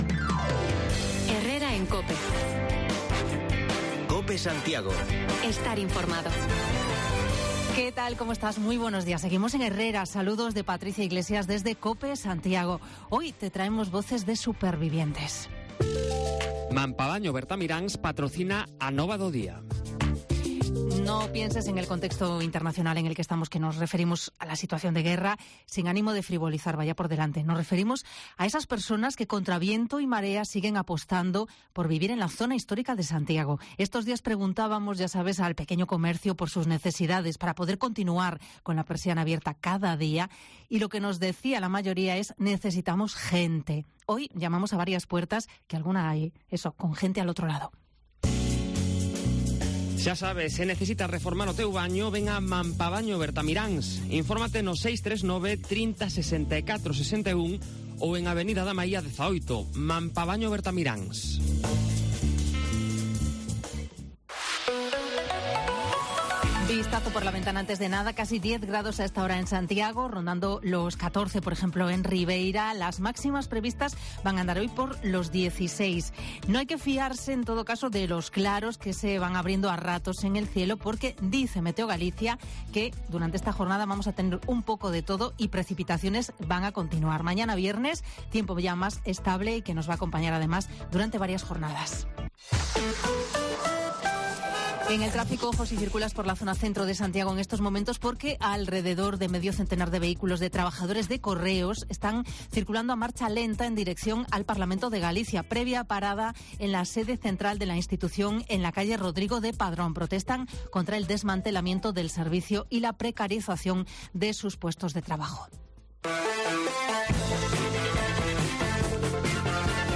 Actualizamos lo más destacado de este último día de marzo, con voces de "supervivientes" del Casco Histórico: vecinos que a pesar de las dificultades, siguen residiendo en esta zona que ha perdido un 25% de habitantes desde principios de 2000